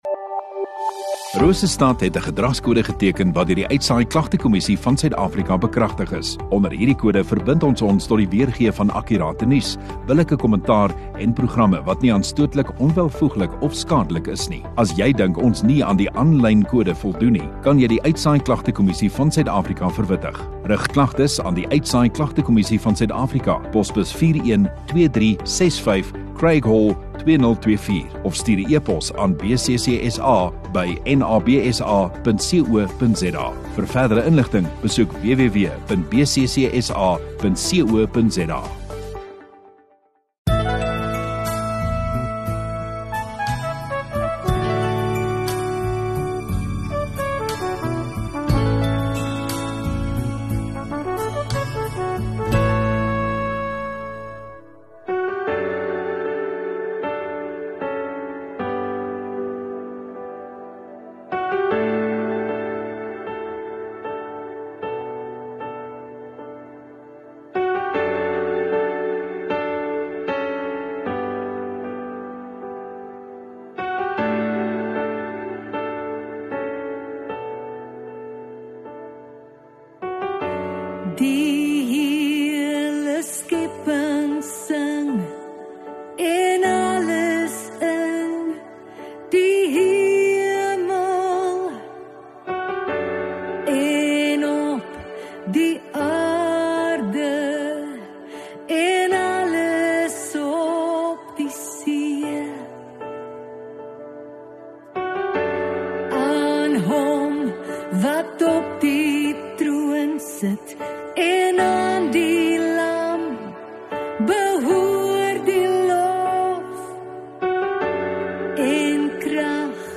23 Dec Maandag Oggenddiens